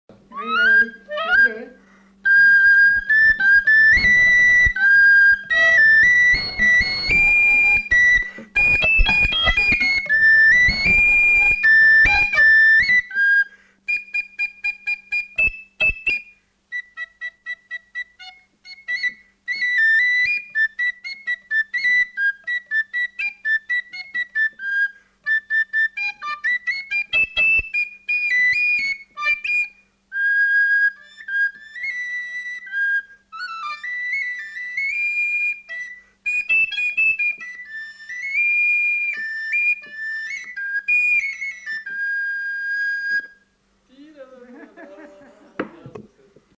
Genre : morceau instrumental
Instrument de musique : flûte à trois trous
Danse : valse